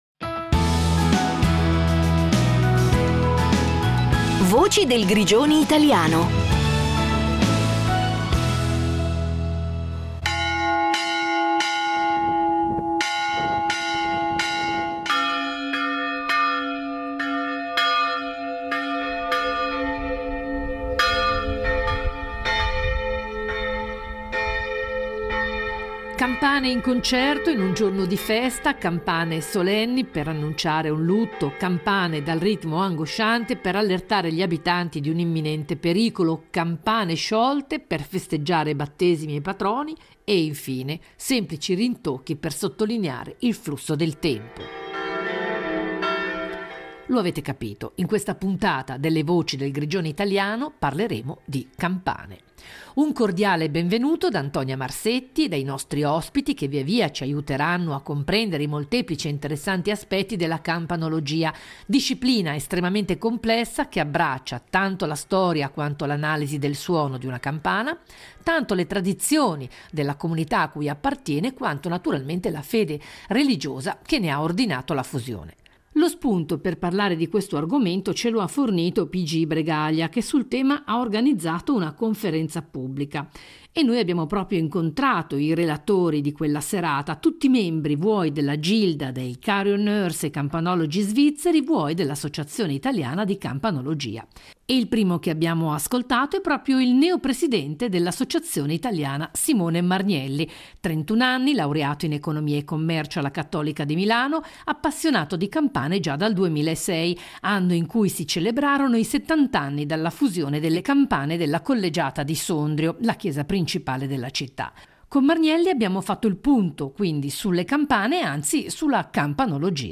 Ebbene non è così e ve ne diamo prova in questa puntata de Le Voci del Grigione Italiano dove sentirete - oltre al suono di svariate campane - anche la voce di tre giovani campanologi che ci guideranno alla scoperta di un mondo davvero affascinante e ai più sconosciuto.